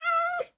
Minecraft Version Minecraft Version 1.21.5 Latest Release | Latest Snapshot 1.21.5 / assets / minecraft / sounds / mob / cat / stray / idle4.ogg Compare With Compare With Latest Release | Latest Snapshot